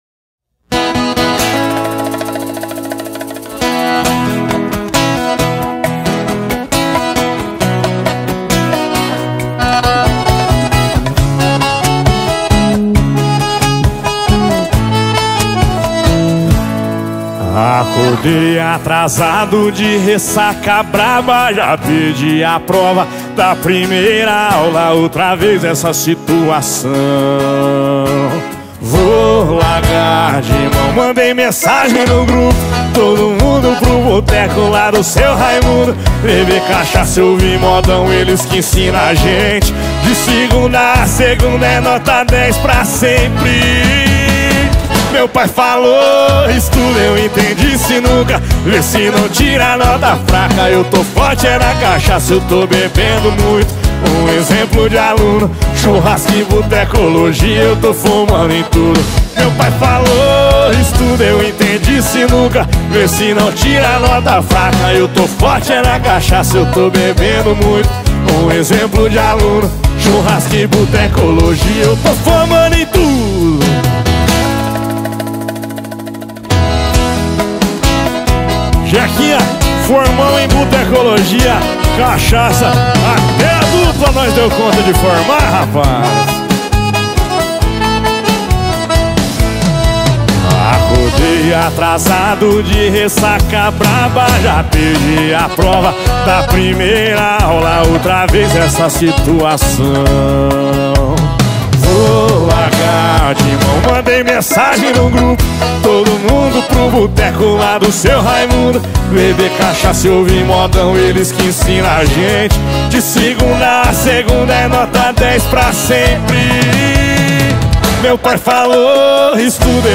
2024-02-25 18:55:22 Gênero: Forró Views